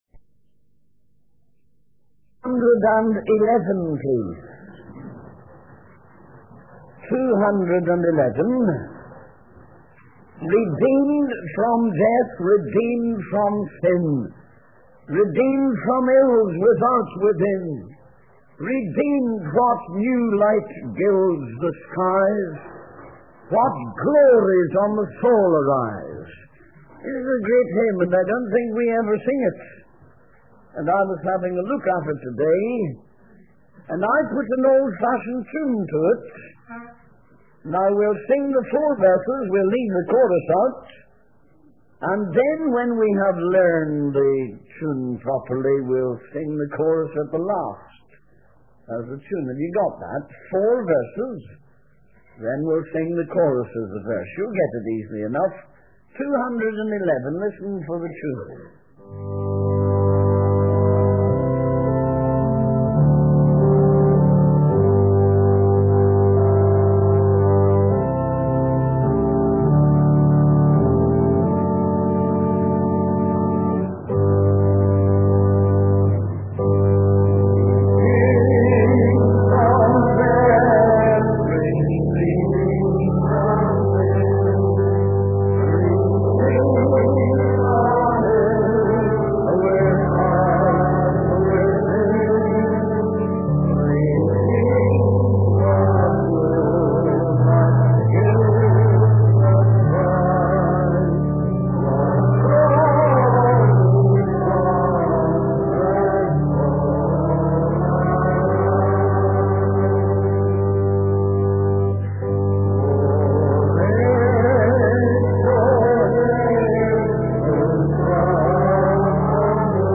The sermon includes a hymn about the passing of time and the impending judgment. The preacher also references a biblical passage from Revelation 20, highlighting the importance of living a righteous life as every action will be recorded in the book of life.